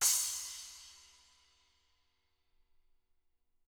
Index of /90_sSampleCDs/ILIO - Double Platinum Drums 1/CD4/Partition H/SPLASH CYMSD